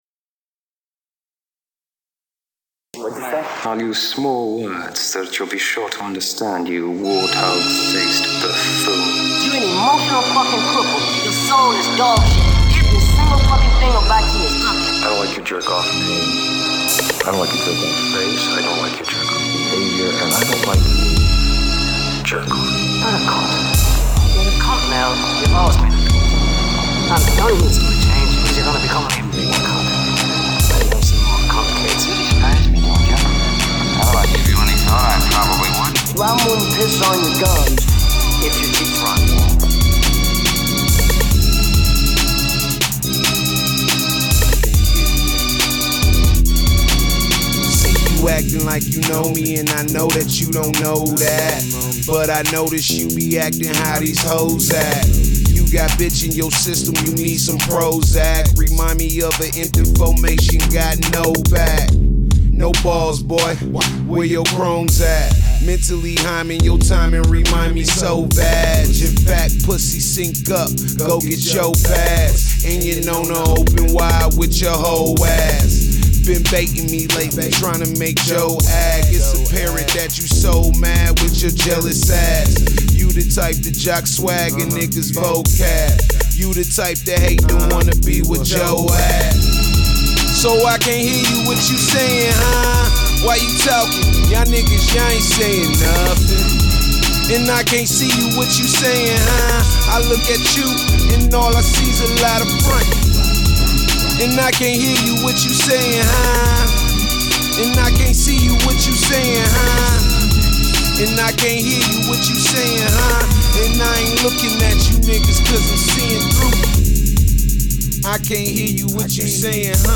Hiphop
Description : Hip Hop/ Rap